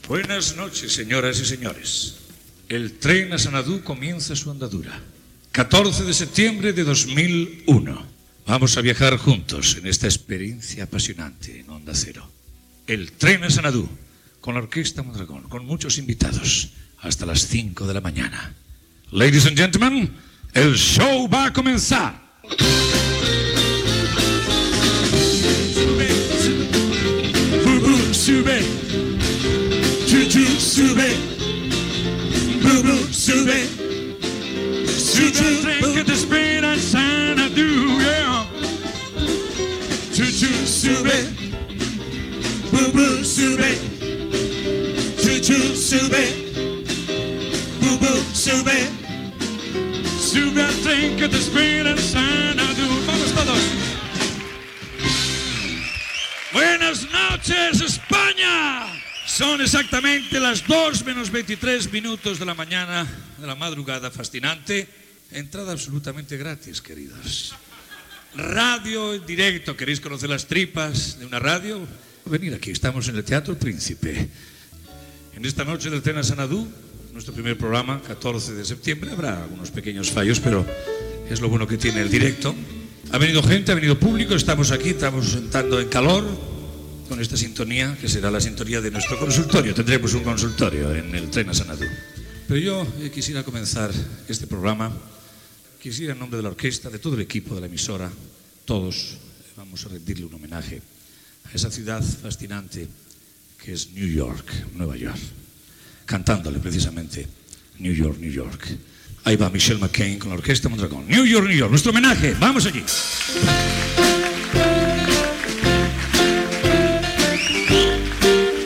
Primera edició del programa, Presentació, cançó del programa interpretada en directe en el programa fet cara al públic des del Teatro Príncipe de Madrid. Hora, lloc on es fa el programa, salutació al públic assistent, homenatge a Nova York i tema musical
Entreteniment